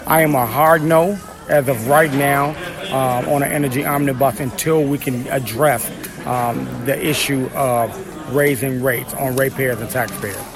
State Sen. Willie Preston said another line item on a utility bill could crush working-class people.